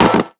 error-notification-pop-in.wav